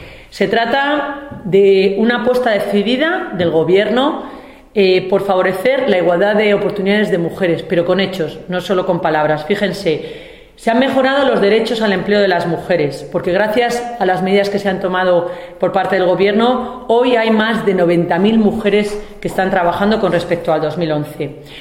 La senadora del PP Ana González ha manifestado hoy en rueda de prensa su satisfacción por las medidas adoptadas por el Gobierno de Mariano Rajoy en el último Consejo de Ministros que se plasman en más derechos y más igualdad de oportunidad para las mujeres.